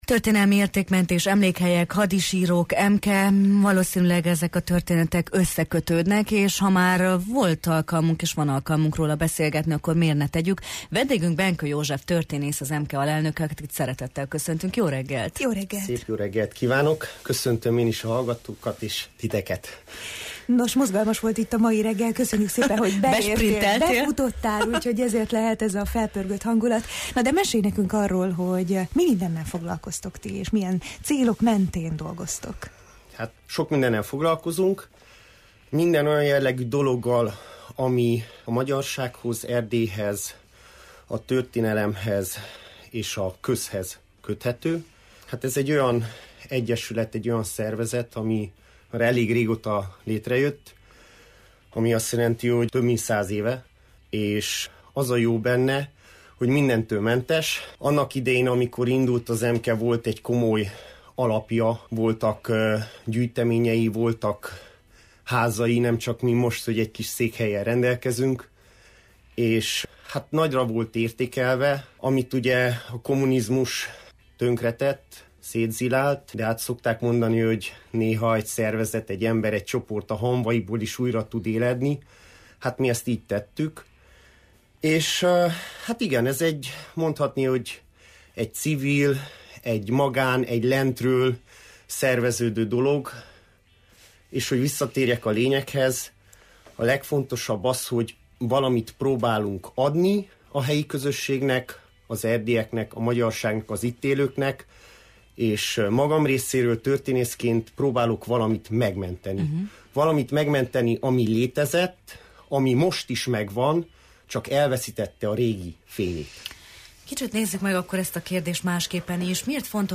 történésszel beszélgettünk